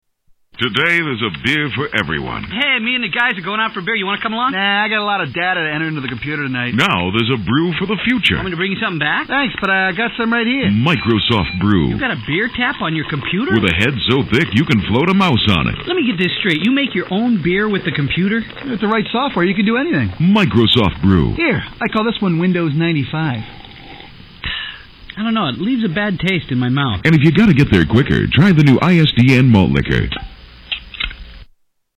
Tags: Comedy Commercials Funny Commercials Commercials Comedy Funny